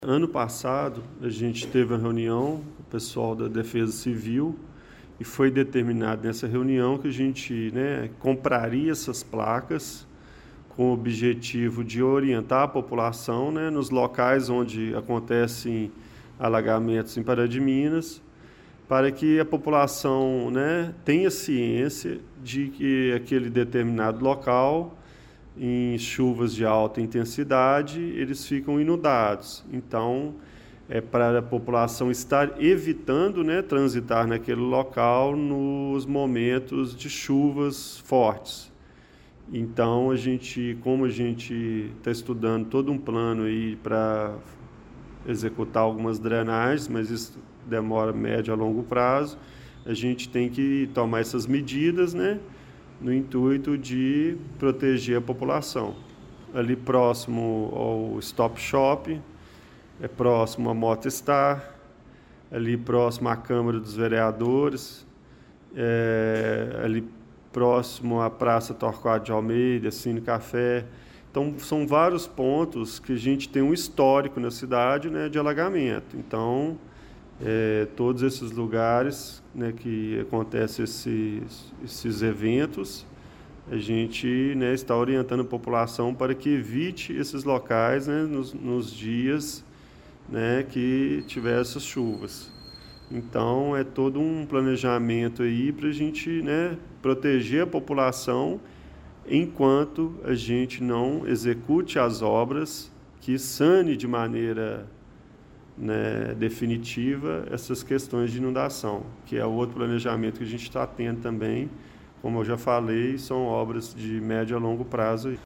O Portal GRNEWS ouviu o secretário Dimitri Gonçalves de Morais que explica que a intenção era instalar as placas desde o ano passado, para alertar a população: